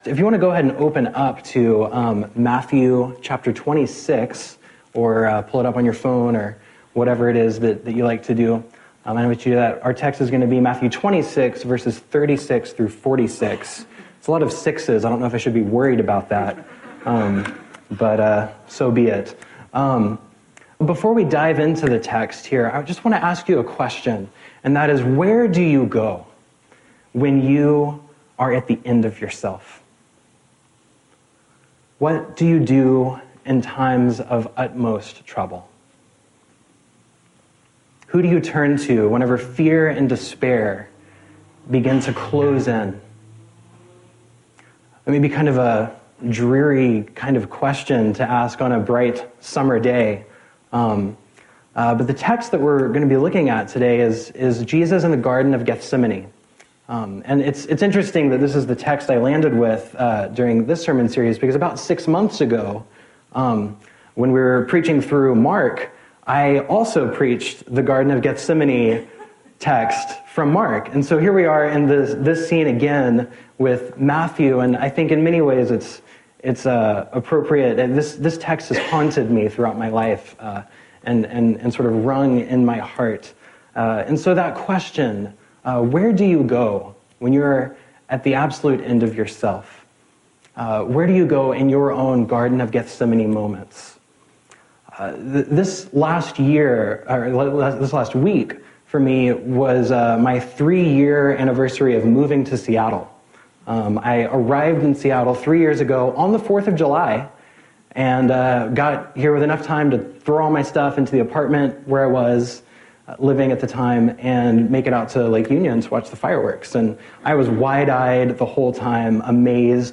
In the summer of 2017, I contributed to a sermon series called “Revealing the Father” at Sanctuary Church.